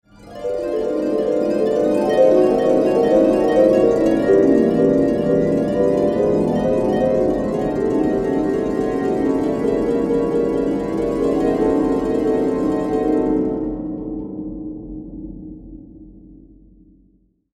Whimsical Harp Sound Effect
Whimsical-harp-sound-effect.mp3